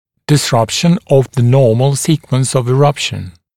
[dɪs’rʌpʃn əv ðə ‘nɔːml ‘siːkwəns əv ɪ’rʌpʃn] [дис’рапшн ов зэ ‘но:мл ‘си:куэнс ов и’рапшн] нарушение нормальной последовательности прорезывания